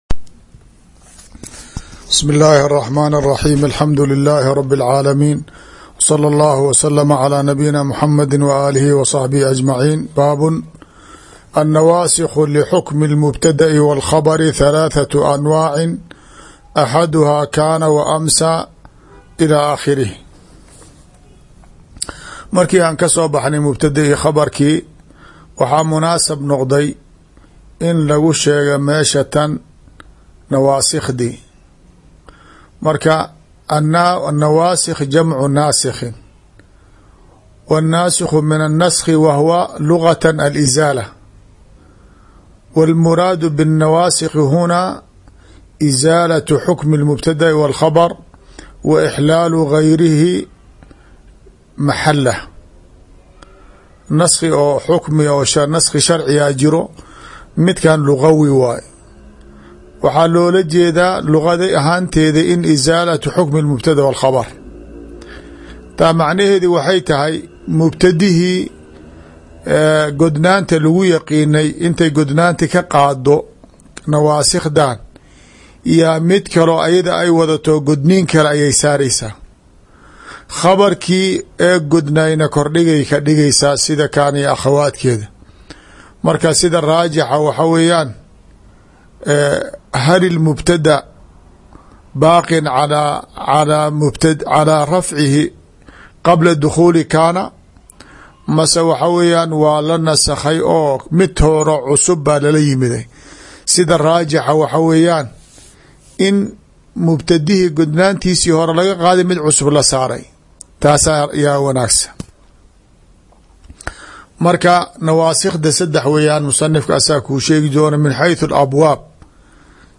Qadru Nadaa ll Darsiga 20aad